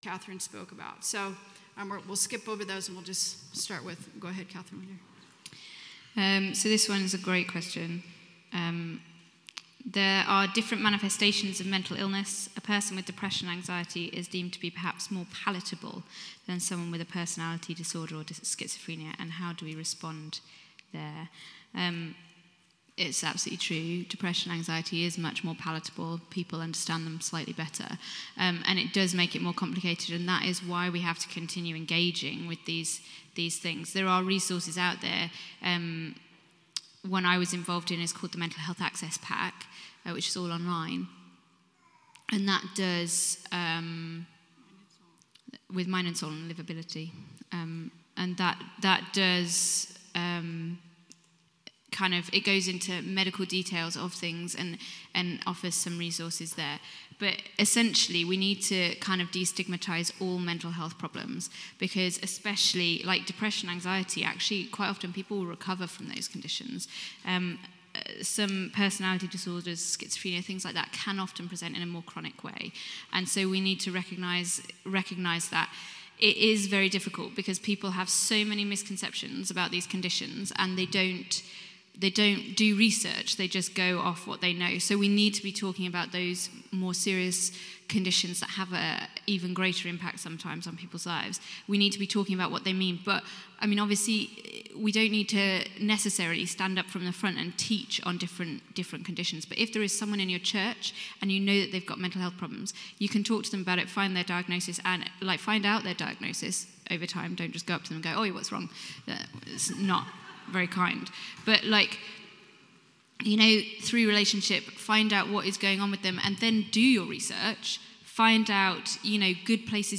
Conference - Saturday 7th October 2017